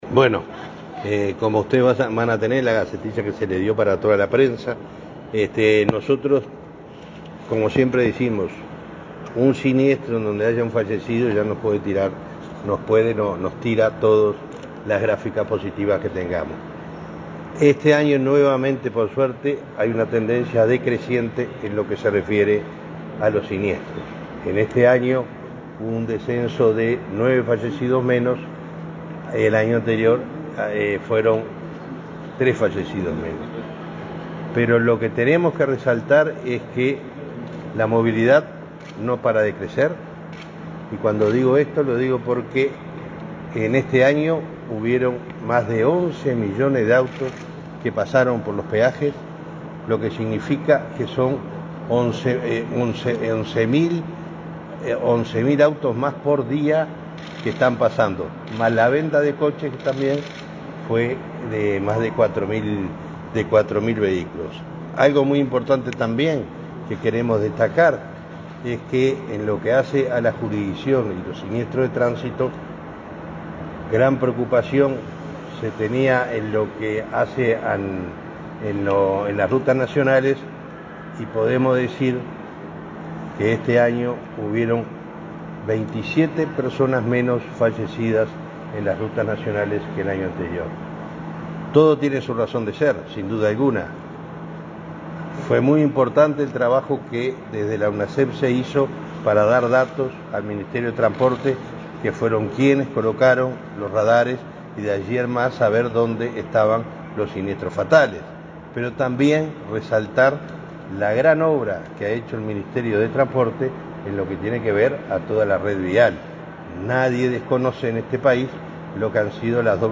Declaraciones del presidente de Unasev, Alejandro Draper
Declaraciones del presidente de Unasev, Alejandro Draper 13/03/2024 Compartir Facebook X Copiar enlace WhatsApp LinkedIn El presidente de la Unidad Nacional de Seguridad Nacional (Unasev), Alejandro Draper, dialogó con la prensa, luego de presentar, este miércoles 13 en la Torre Ejecutiva, los datos de siniestralidad vial correspondientes al año 2023.